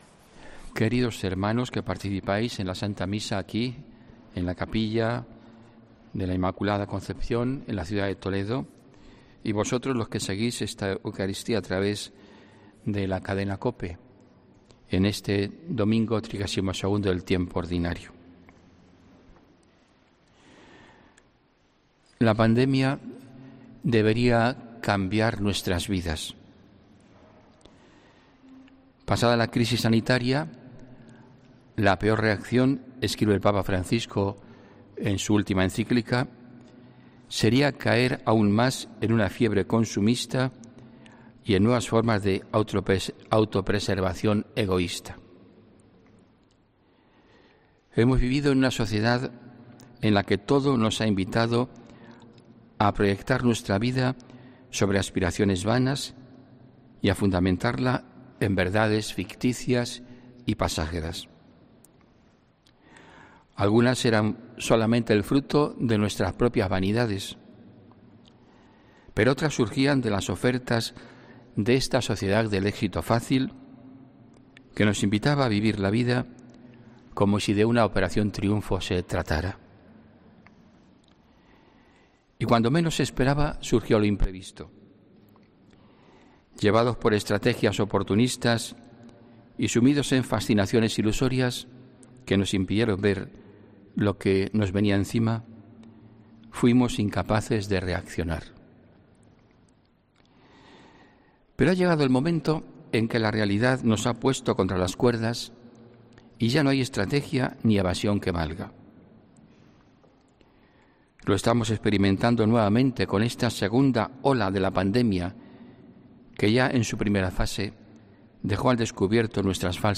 HOMILÍA 8 NOVIEMBRE 2020